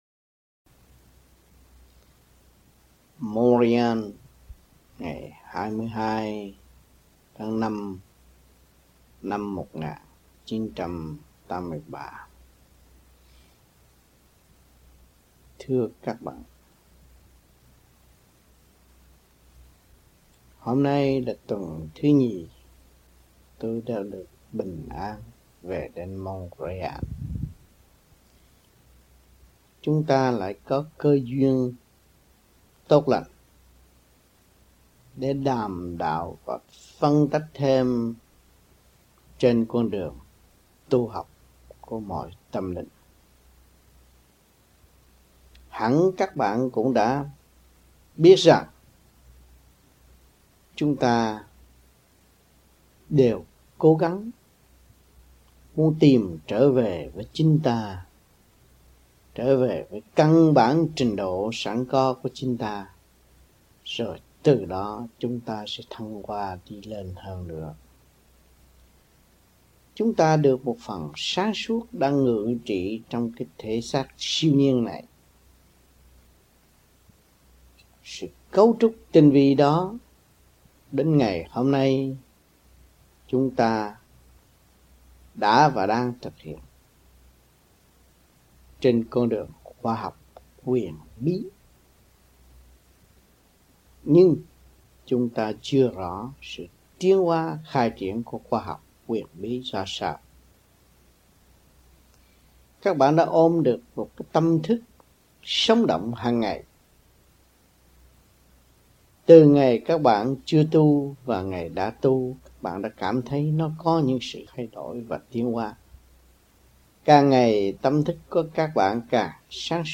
Băng Giảng